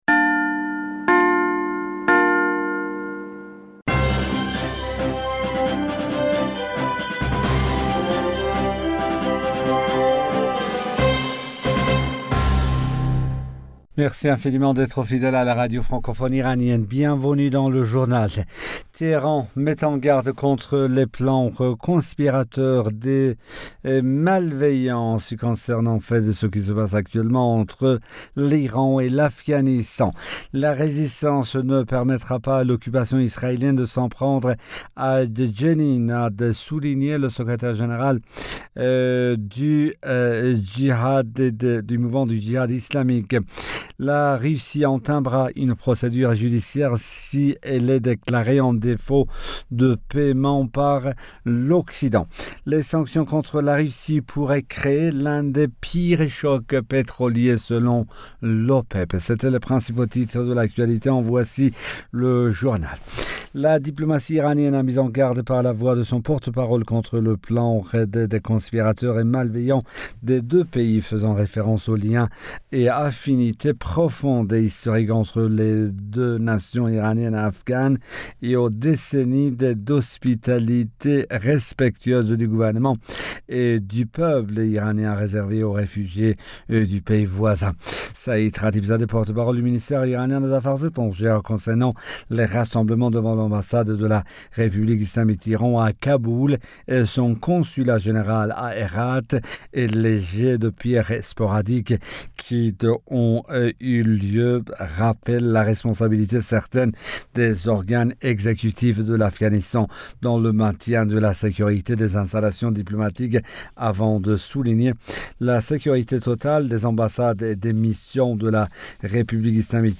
Bulletin d'information Du 12 Avril 2022